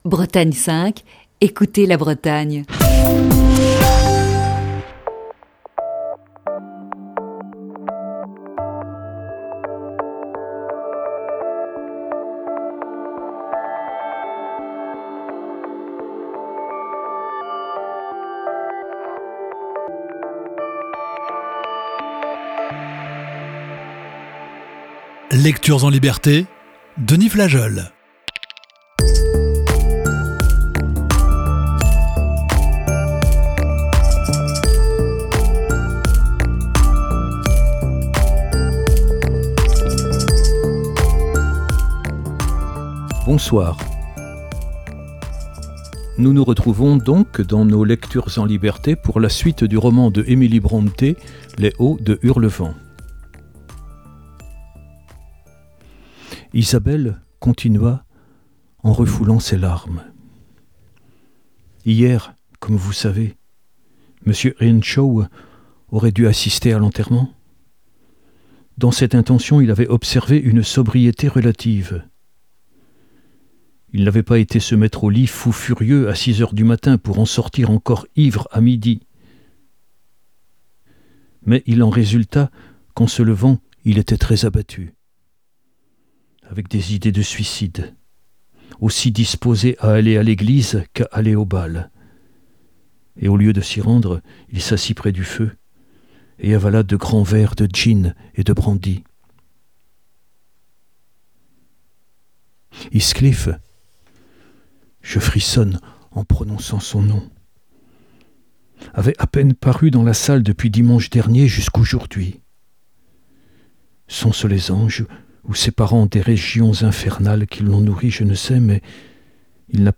Voici ce mercredi la dix-huitième partie de ce récit.